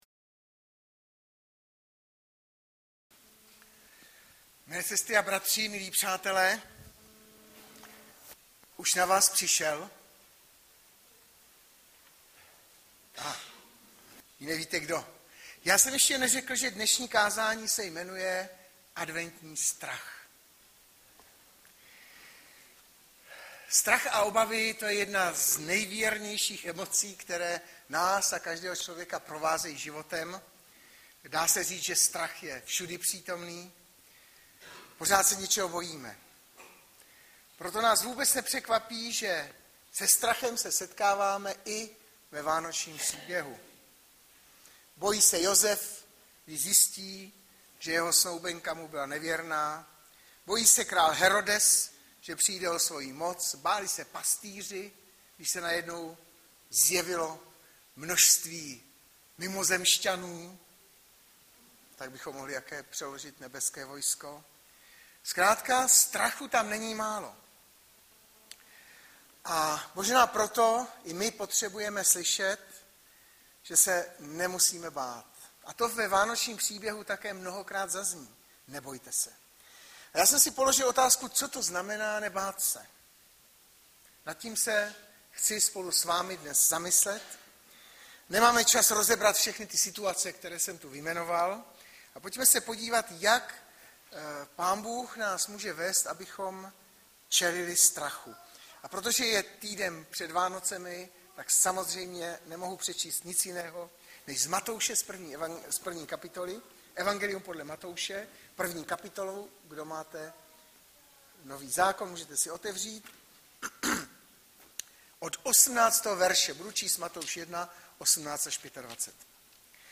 Baptisté v Litoměřicích